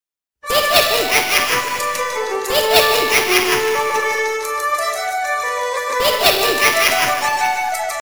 Irish Tune with Evil Laff - Free Ringtone Download